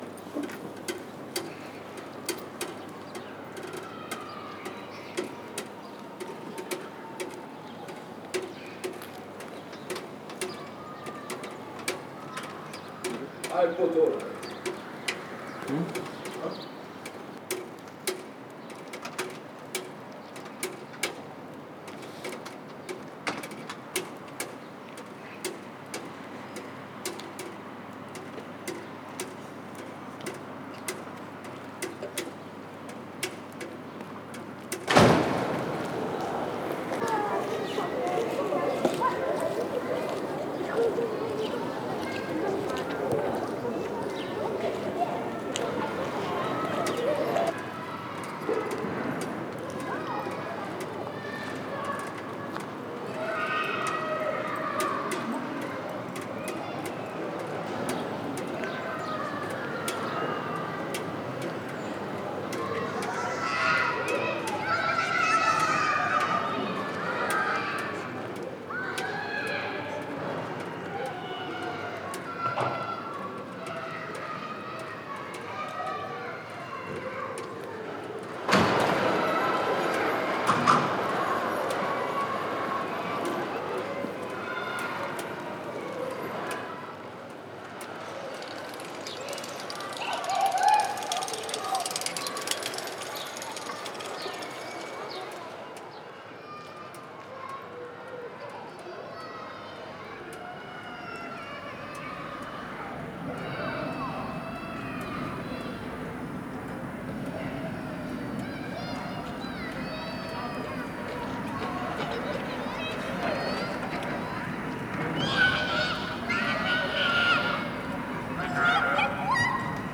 Occuper l’environnement sonore des Baladins : un dialogue entre enfants et corneilles
Voici une restitution de quelques éléments sonores perçus lors de notre exploration du quartier des baladins, où cris d’enfants et de corneilles sont rois.